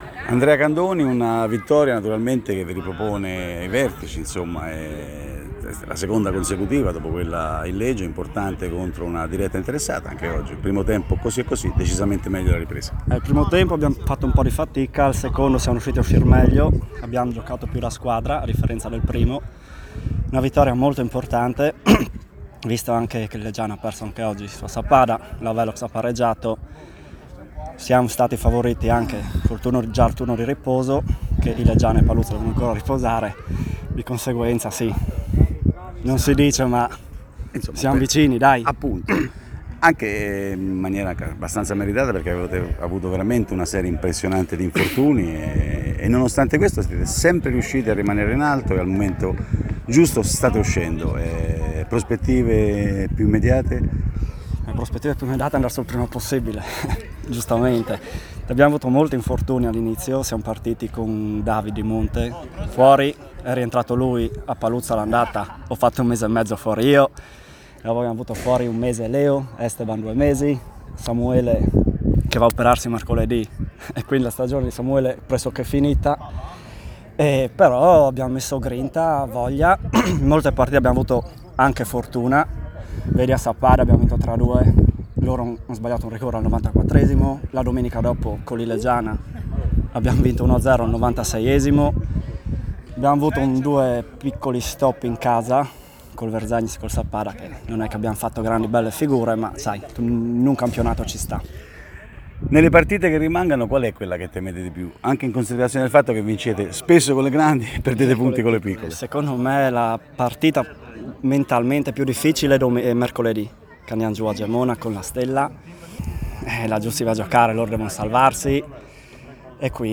Proponiamo l’intervista realizzata al termine di Folgore-Paluzza 2-1